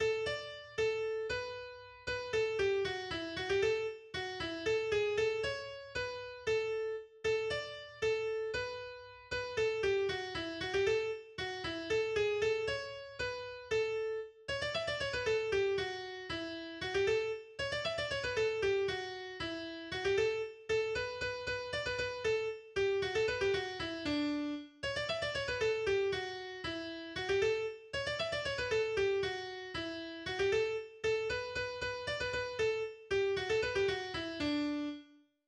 geistliches Weihnachtslied